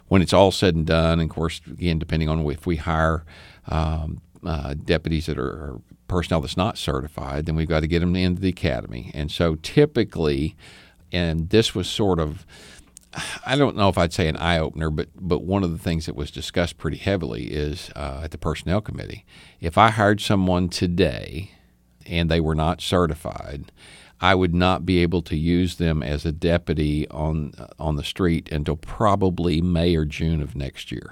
During Tuesday’s Talk of the Town program on KTLO-FM, Sheriff John Montgomery said although the positions have been approved, it was just the first step.